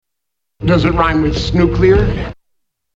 Tags: 3rd Rock from the Sun TV sitcom Dick Solomon John Lithgow Dick Solomon clips